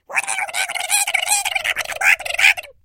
Звуки ругательств
На этой странице собраны звуки ругательств в разных вариациях: от эмоциональных выкриков до забавных перепалок.
Звук ругани когда тебя окутывают бранью